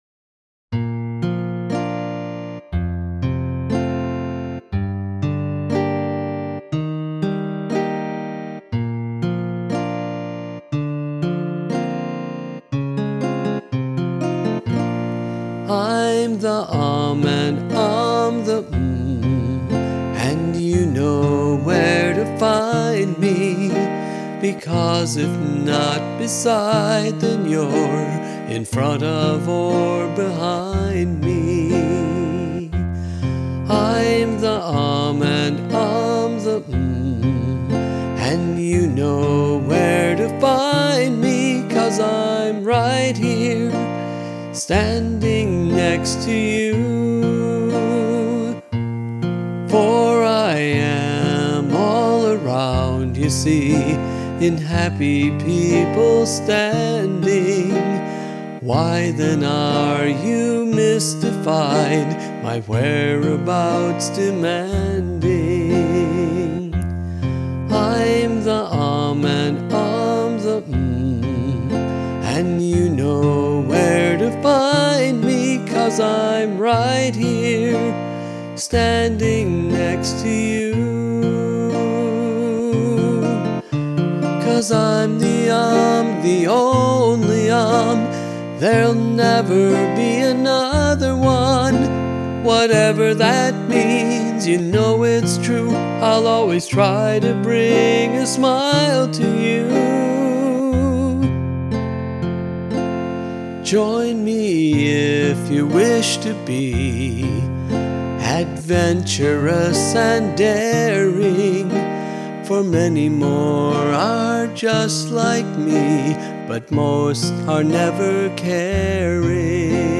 A virtual nylon string guitar provides the only accompaniment for this song, and the vocal is “naked,” with no dynamic compression at all. This gives the song a “live” feel.